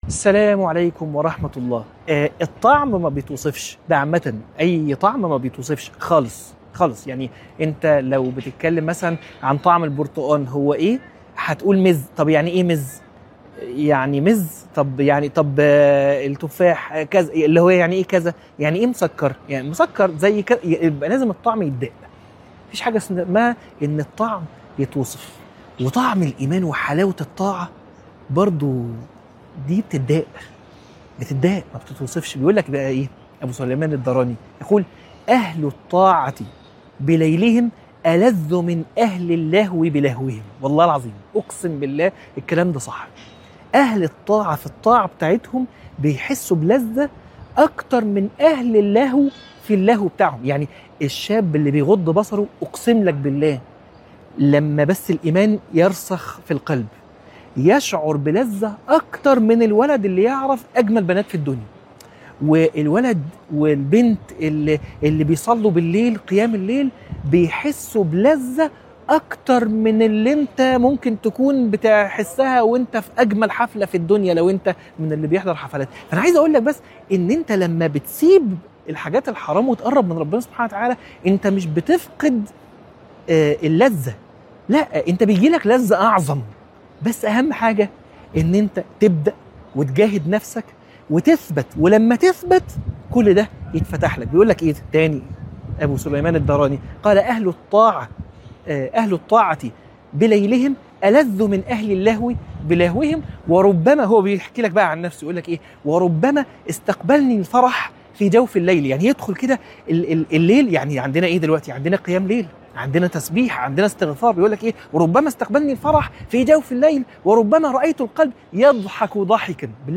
عنوان المادة حاجات ما بتتوصفش - من الحرم